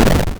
explosion(1).wav